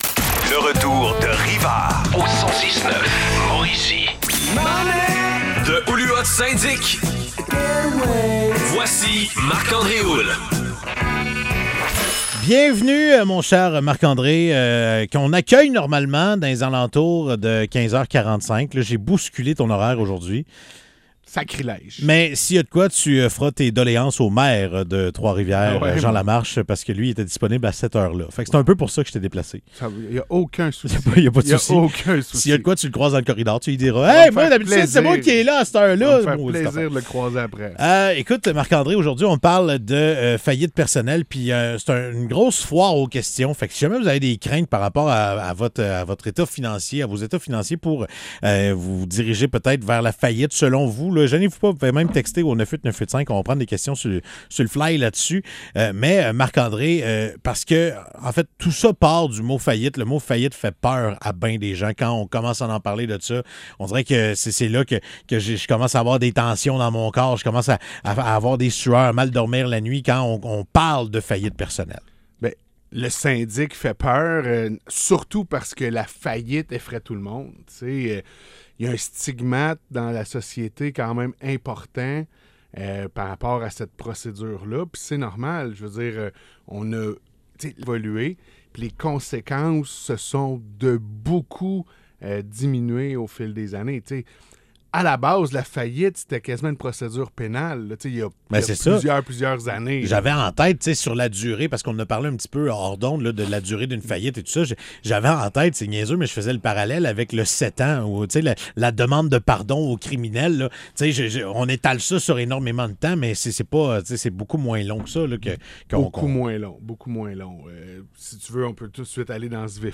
Chronique Radio 106.9 Fm